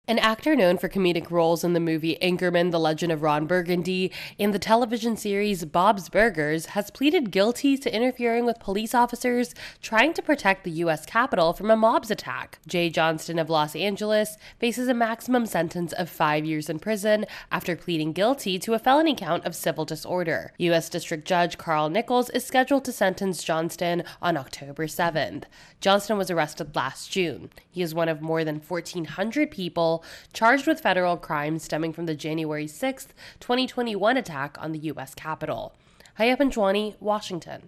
AP correspondent reports on an arrest charge for a comedy actor in relation to the Jan. 6 attacks.